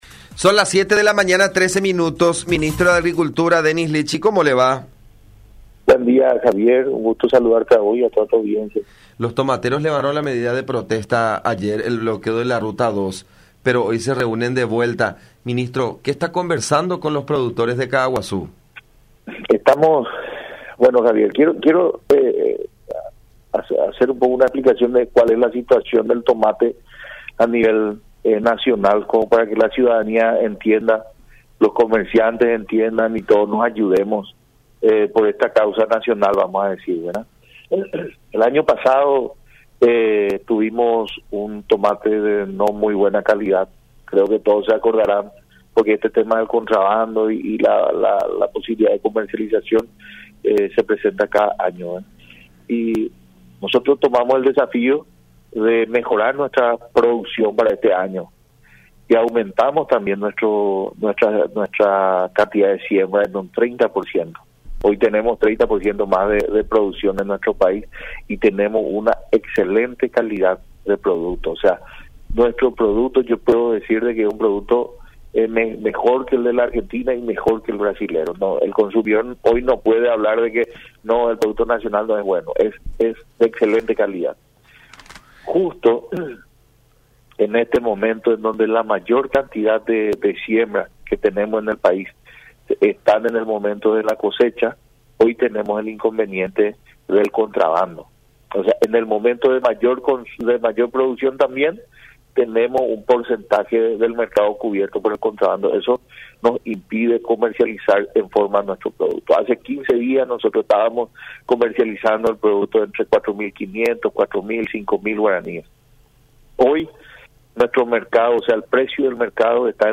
09-Denis-Lichi-Ministro-de-Agricultura-sobre-situación-de-los-productores-de-tomate.mp3